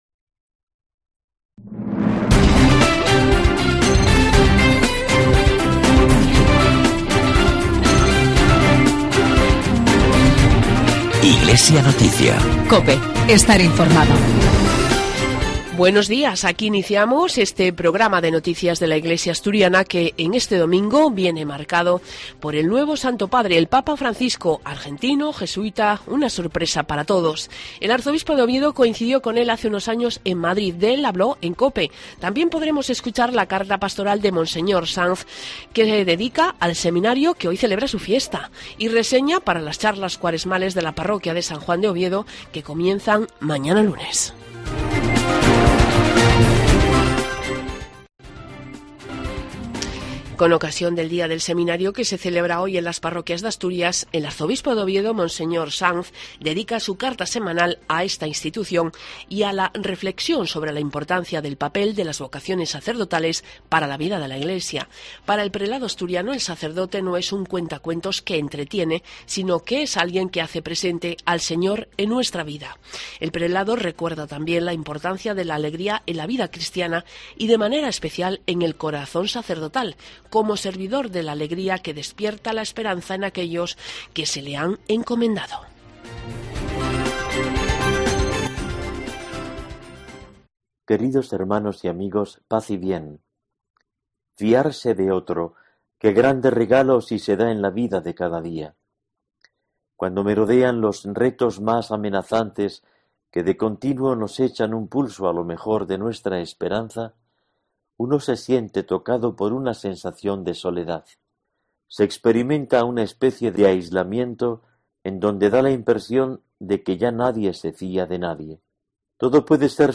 Programa de noticias de la Iglesia de Asturias 17 de marzo de 2013